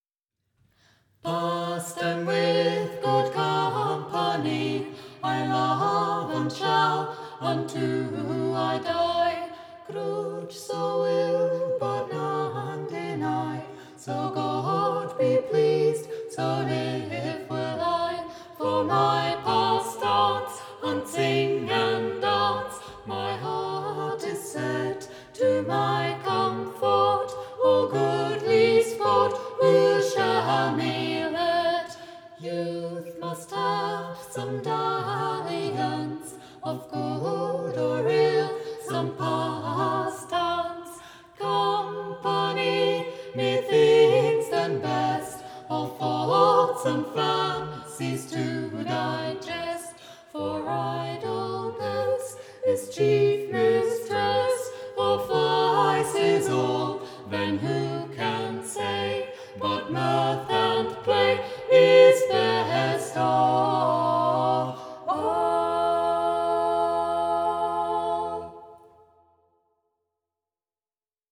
eine Familie und ein A Capella-Trio. Wir singen dreistimmige Lieder aus aller Welt, z.B. Griechenland, Frankreich, Georgien, Irland, dem Alpenland u.v.m. Unsere Verbindung untereinander und die Liebe zum Gesang ist für uns selbst immer wieder eine große Freude und wirkt sich bei unseren Konzerten auf den ganzen Raum inklusive unsere Zuhörer aus.
Der Klangcharakter des Offenen Vokalensembles ist direkt, authentisch und lebendig, ausgezeichnet durch innere Verbundenheit und Stimmigkeit.